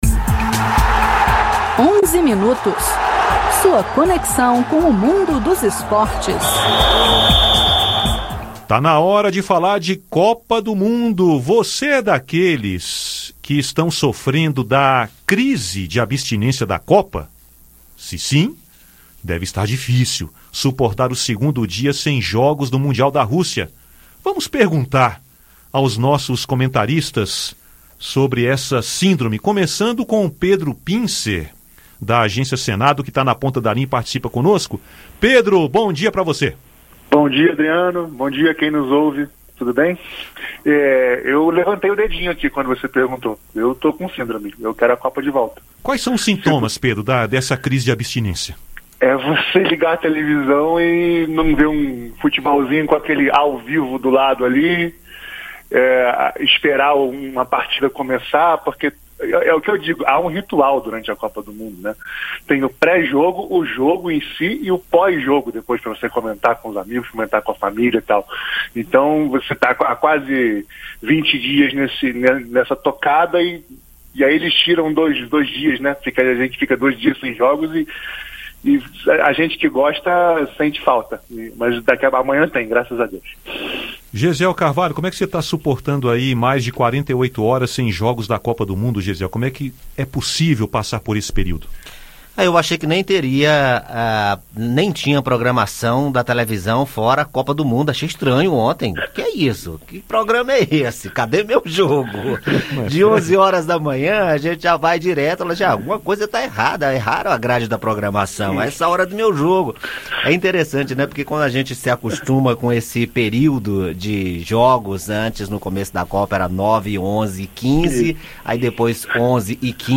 Os comentarista da Rádio Senado debatem sobre a nova fase da Copa. E comentam também sobre a segurança nos estádios de futebol da Rússia e do Brasil.